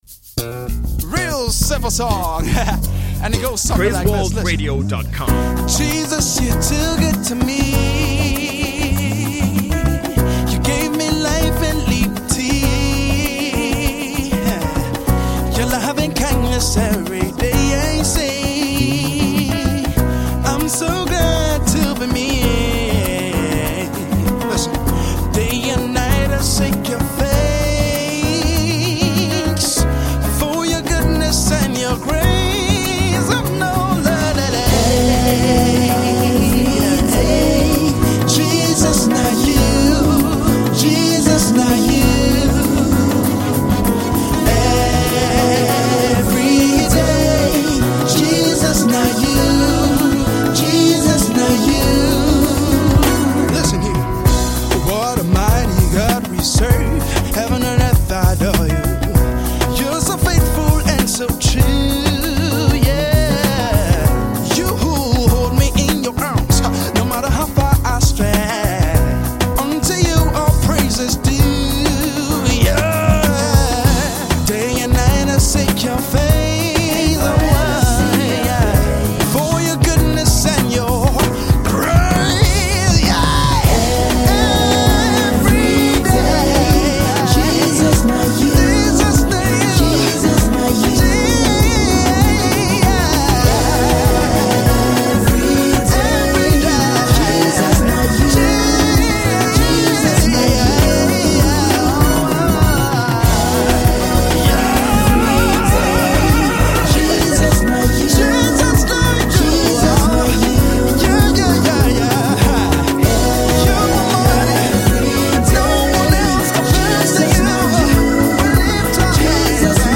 Gospel R&B music group